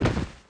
stray_dog
drop_2.wav